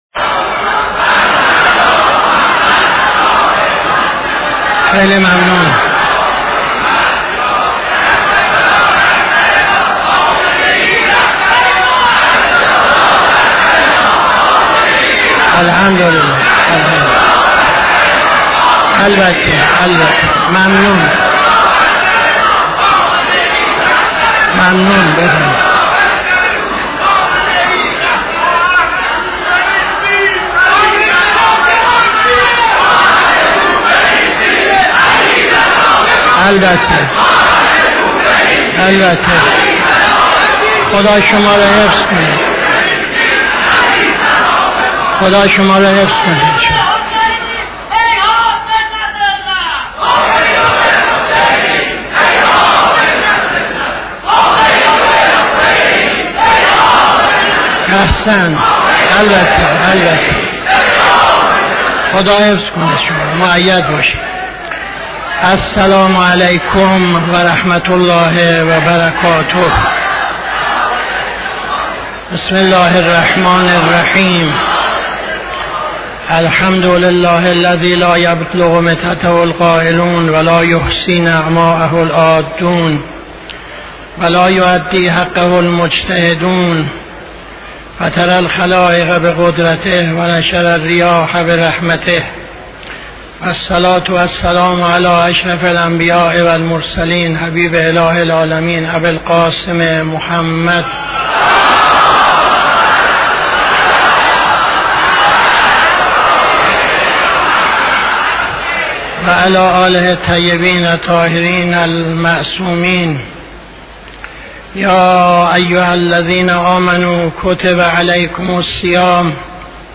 خطبه اول نماز جمعه 02-09-80